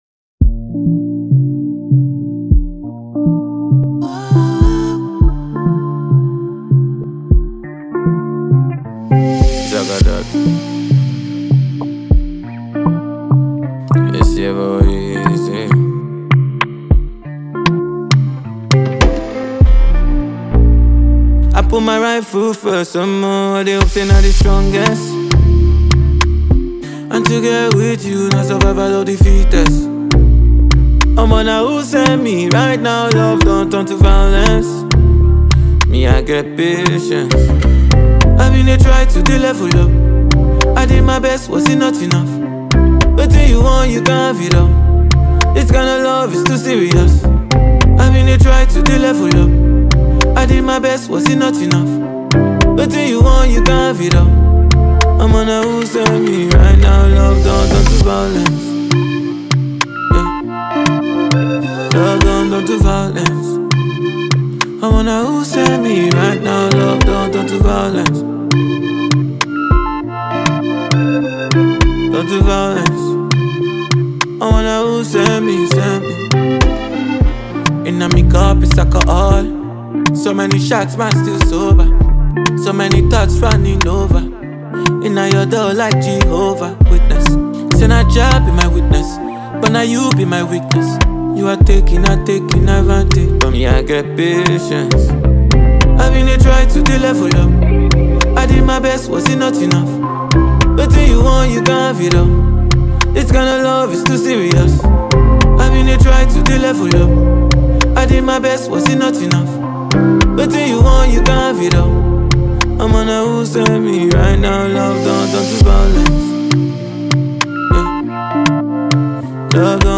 New Hot Afro Tune
Afropop style